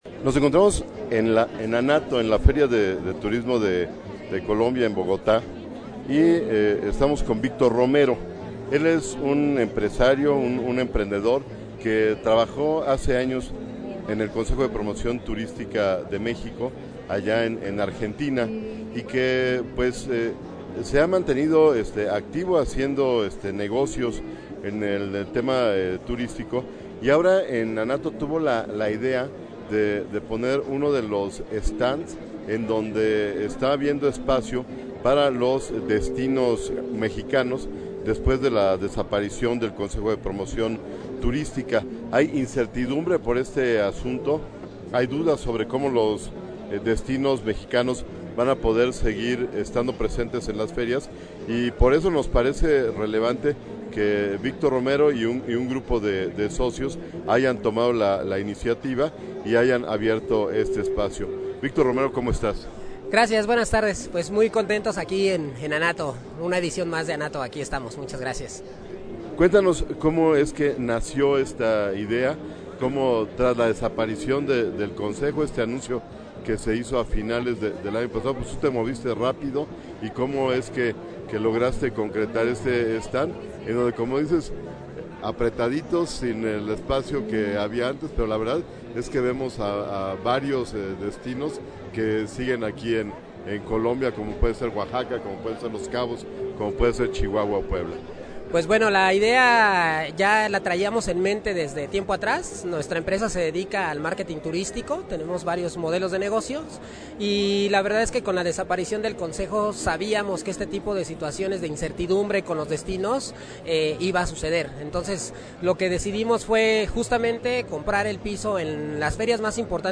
En el marco de la Feria ANATO 2019 de Colombia